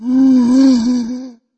Index of /server/sound/npc/firezombie